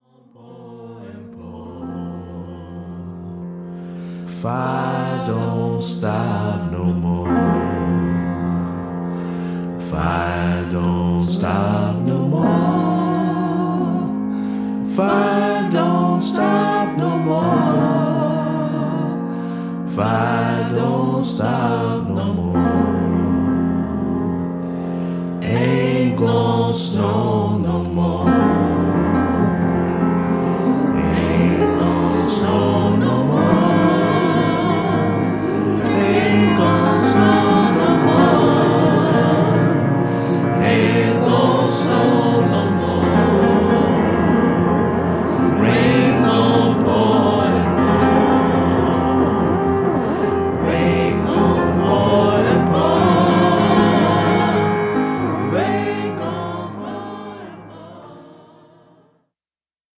コルネット奏者